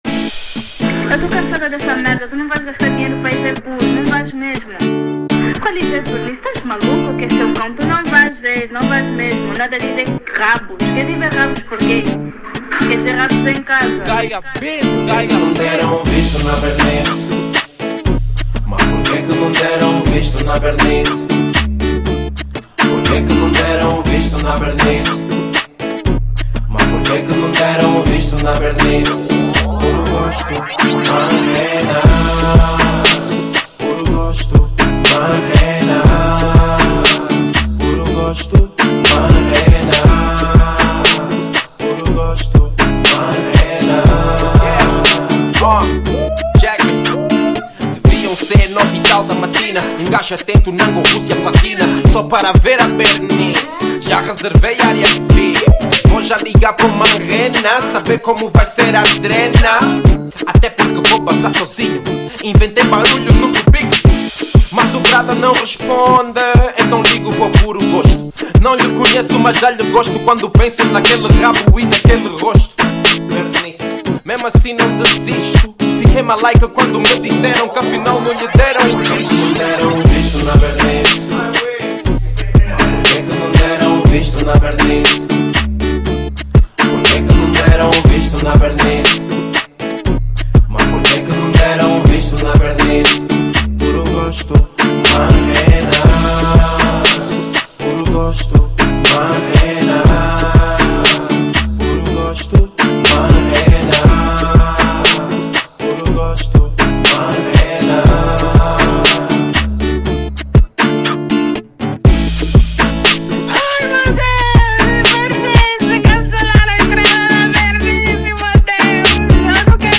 R&B |Sem intro